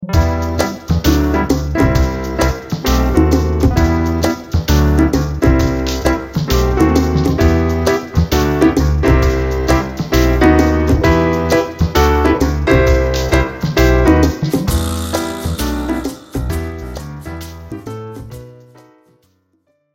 Traditional Spiritual